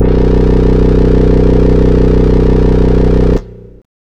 SYNTHBASS1-L.wav